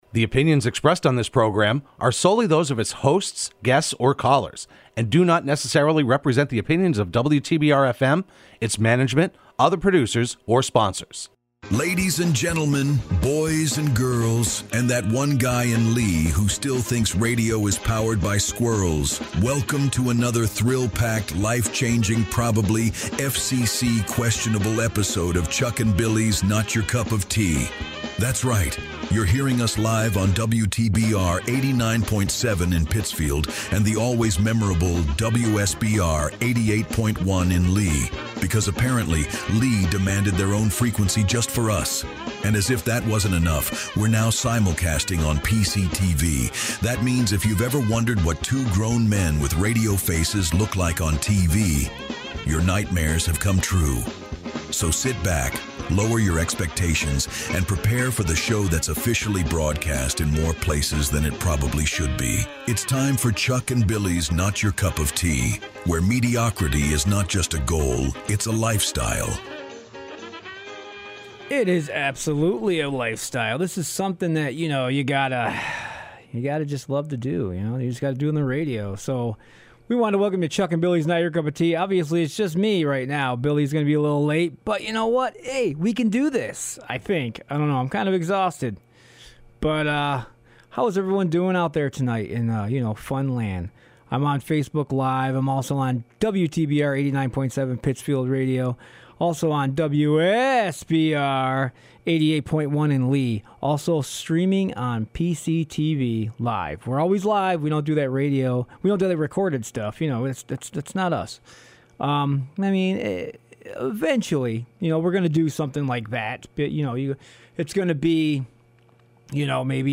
Broadcast live every Wednesday afternoon at 3:30pm on WTBR.